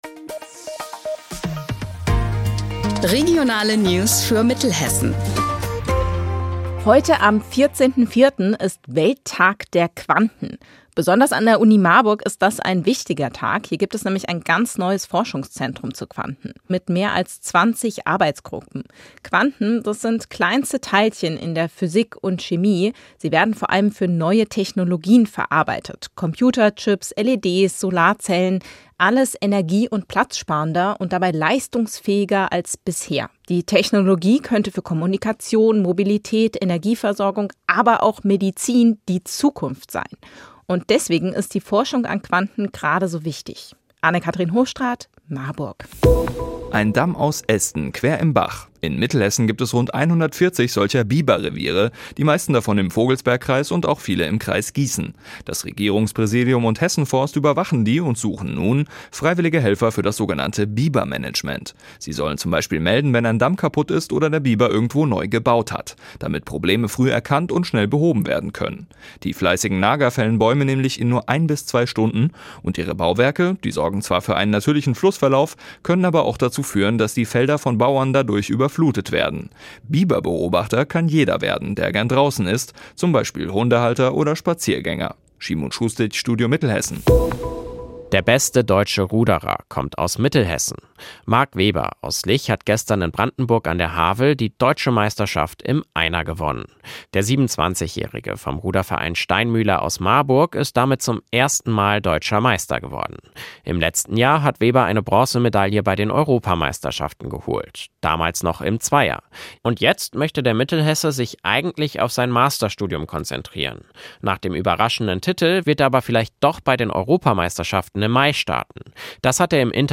Mittags eine aktuelle Reportage des Studios Gießen für die Region